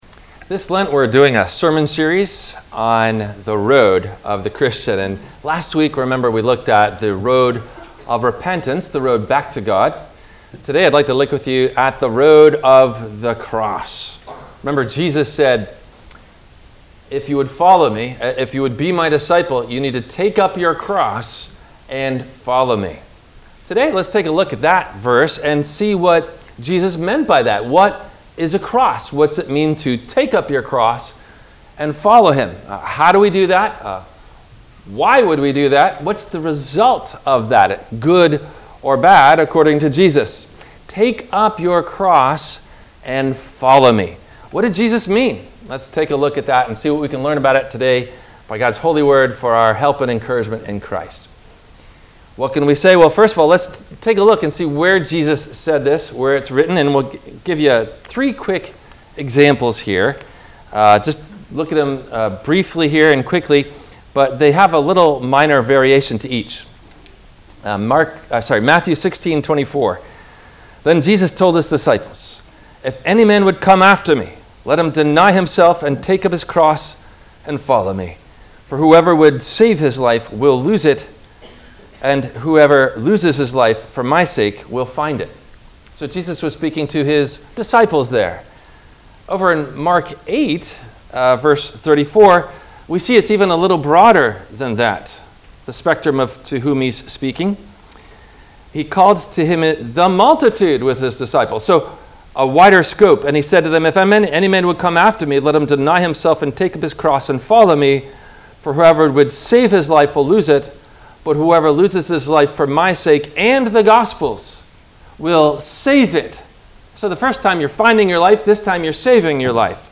WMIE Radio – Christ Lutheran Church, Cape Canaveral on Mondays from 12:30 – 1:00